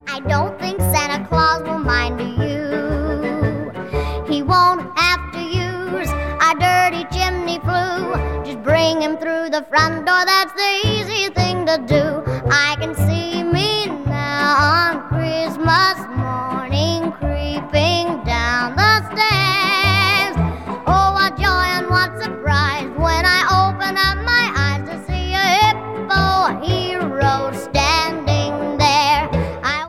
• Childrens Music